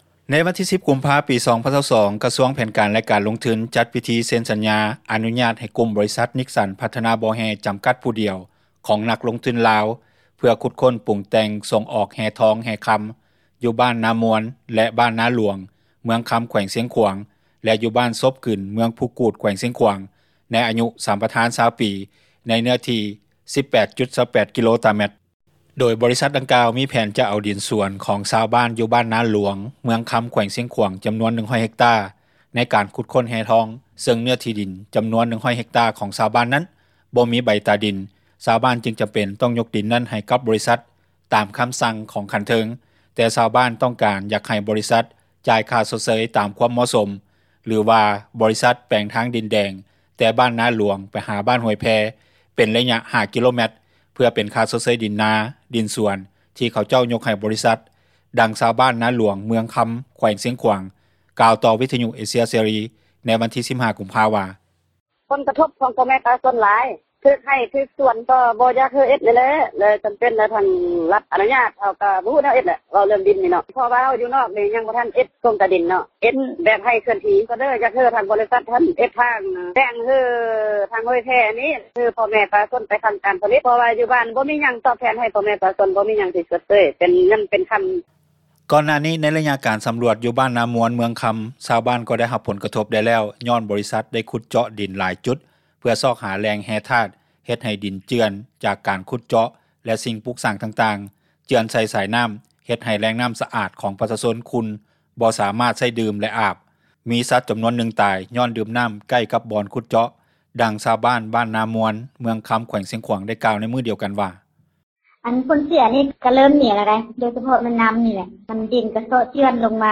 ດັ່ງຊາວບ້ານ ນາຫລວງ ເມືອງຄຳ ແຂວງຊຽງຂວາງ ກ່າວຕໍ່ ວິທຍຸເອເຊັຽເສຣີ ໃນວັນທີ 15 ກຸມພາ ວ່າ:
ດັ່ງຊາວບ້ານ ບ້ານນາມວນ ເມືອງຄຳ ແຂວງຊຽງຂວາງ ກ່າວໃນມື້ດຽວກັນວ່າ:
ດັ່ງເຈົ້າໜ້າທີ່ ທີ່ກ່ຽວຂ້ອງແຂວງຊຽງຂວາງ ກ່າວໃນມື້ດຽວກັນວ່າ: